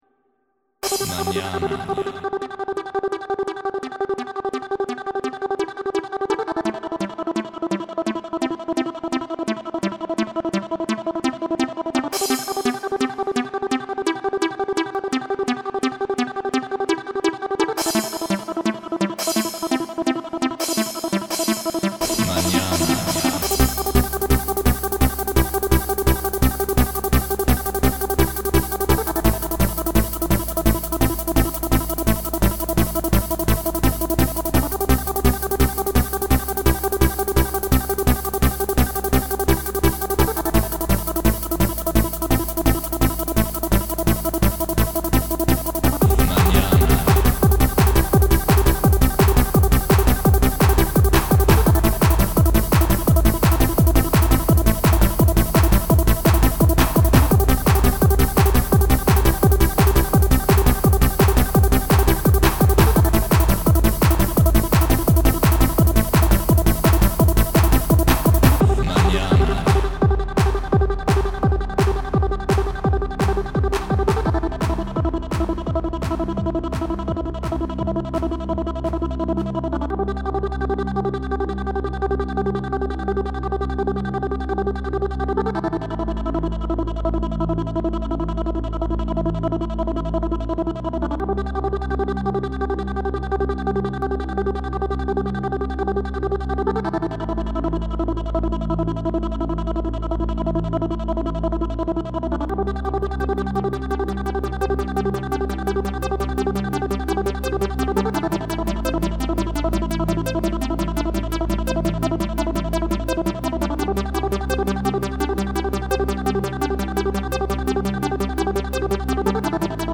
• Jakość: 44kHz, Stereo